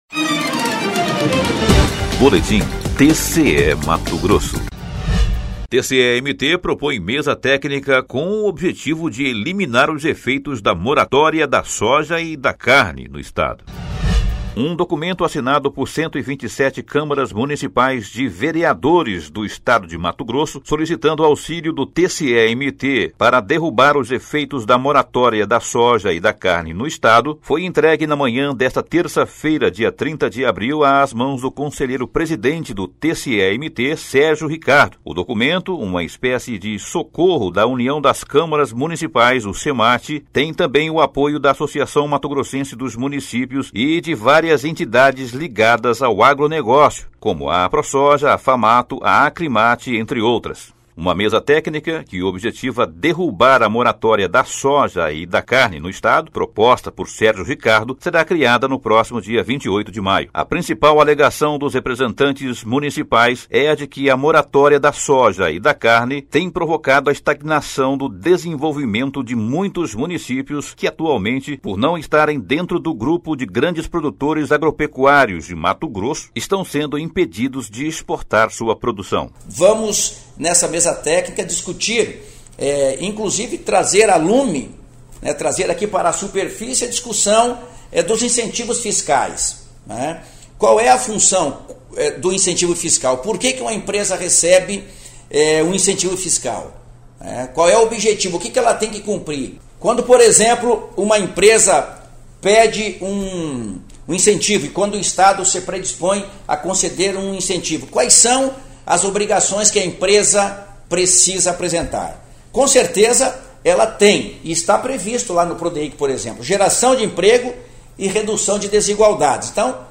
Sonora: Sérgio Ricardo – conselheiro-presidente do TCE-MT
Sonora: Antonio Joaquim – conselheiro do TCE-MT
Sonora: Leonardo Bortolin - presidente da AMM